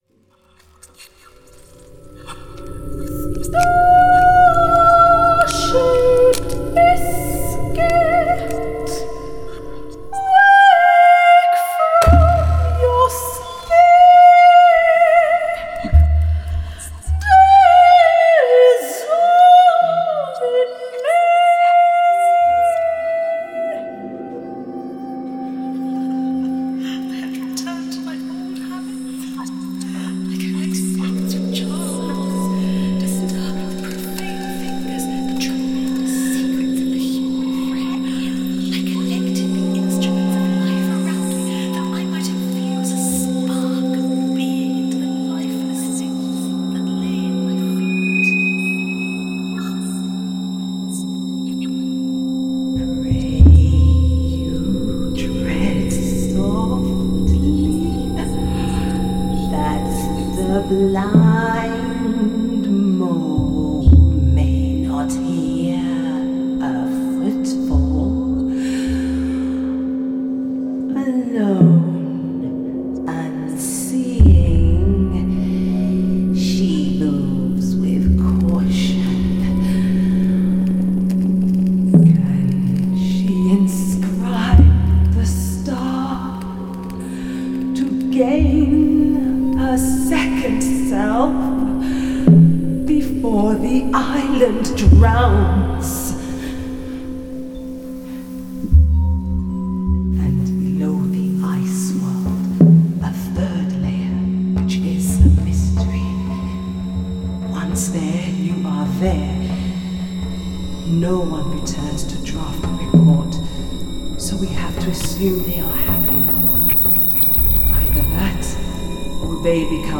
opera recording, Snape Maltings